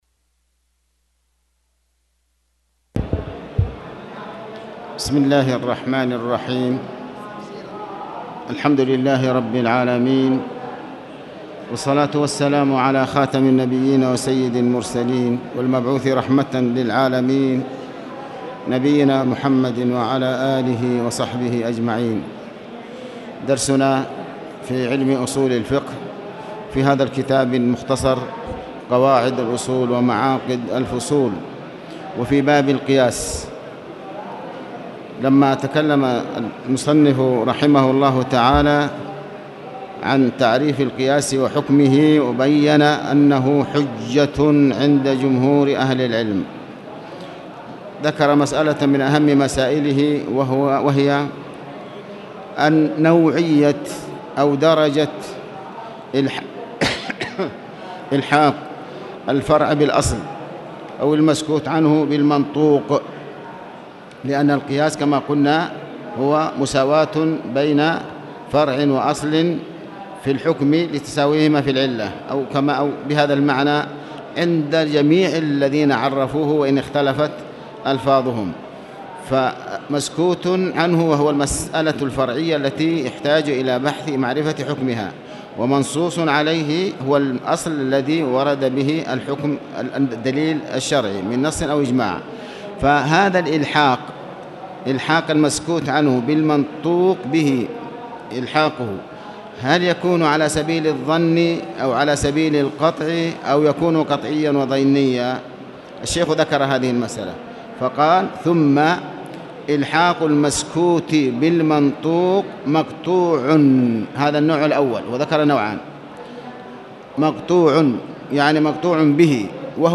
تاريخ النشر ١٨ محرم ١٤٣٨ هـ المكان: المسجد الحرام الشيخ